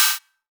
Crashes & Cymbals
Ride Fancy.wav